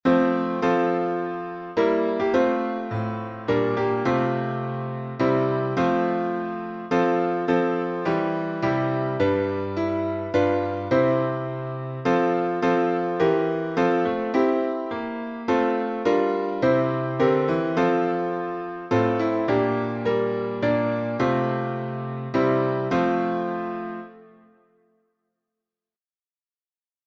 The Lord's My Shepherd, I'll Not Want — alternate chording.
Key signature: F major (1 flat) Time signature: 3/4